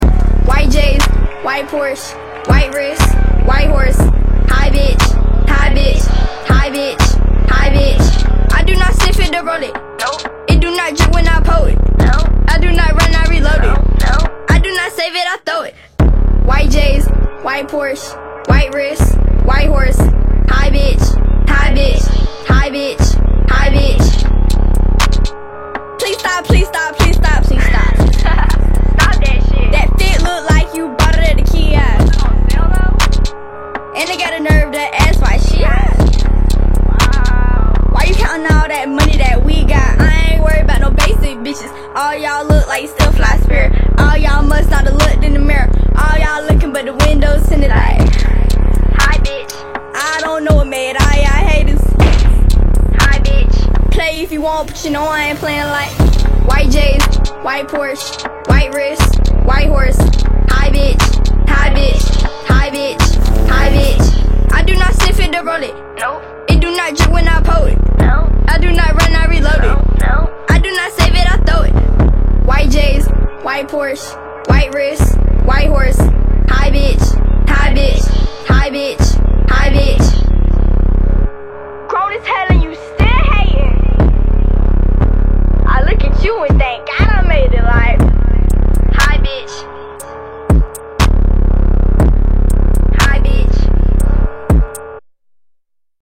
мощные басы
качающие
женский рэп
злые
Стиль: rap, trap